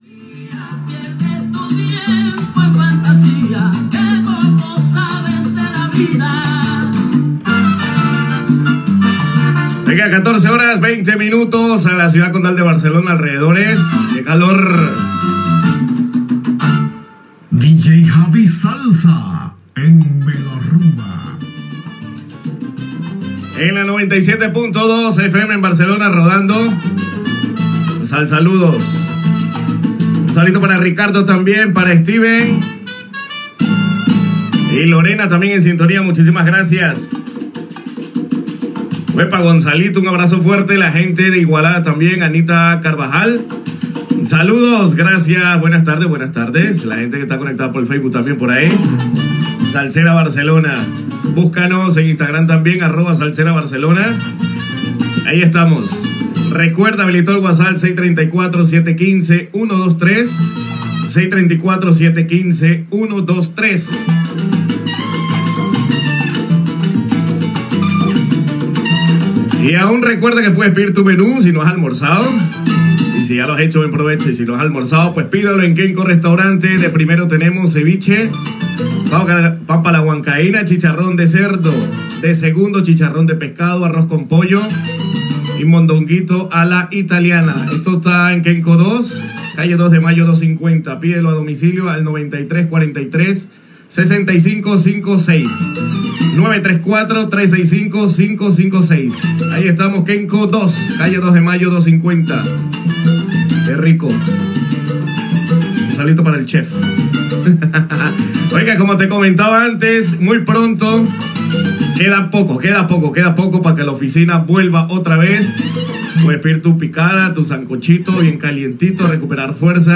Hora, indicatiu del programa, salutacions a l'audiència, publicitat, reapertura de la sala "La oficina", publicitat, telèfon del programa, indicatiu, salutacions i tema musical
Musical
FM